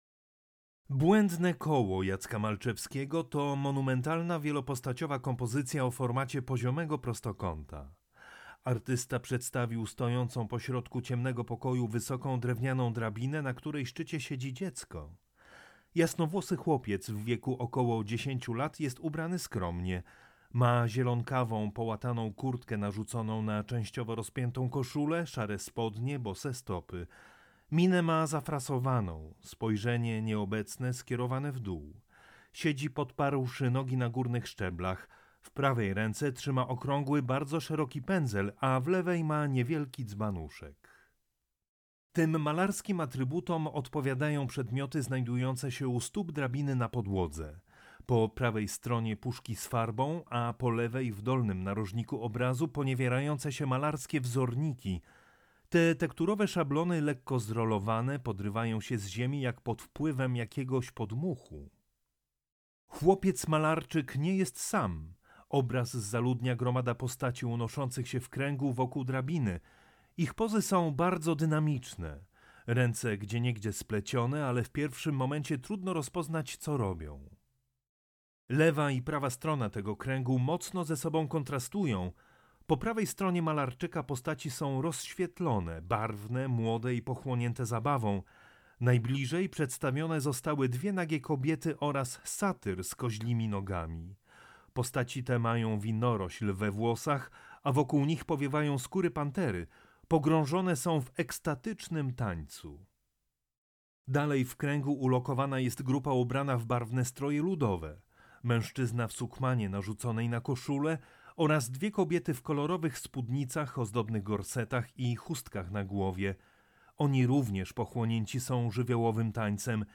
AUDIODESKRYPCJA
AUDIODESKRYPCJA-Jacek-Malczewski-Bledne-kolo.mp3